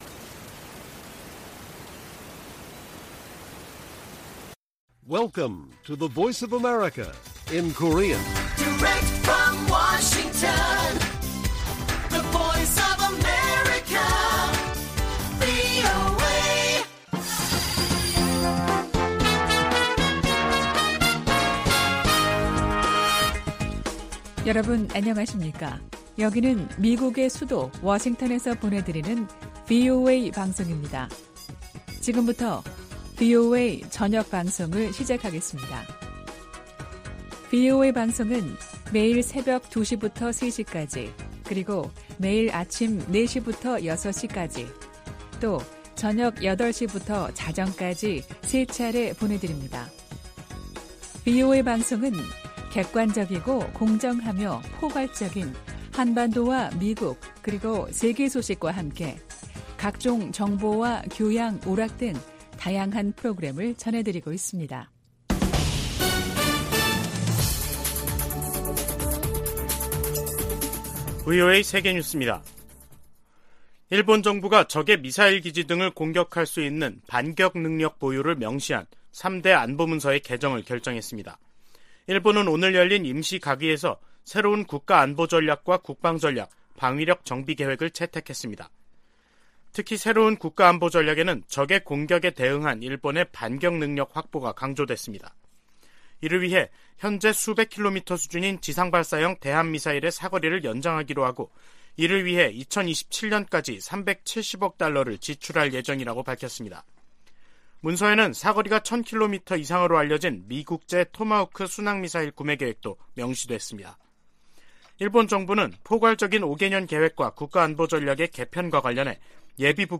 VOA 한국어 간판 뉴스 프로그램 '뉴스 투데이', 2022년 12월 15일 1부 방송입니다. 북한이 대륙간탄도미사일로 보이는 고출력 고체엔진 시험에 성공했다고 주장했습니다.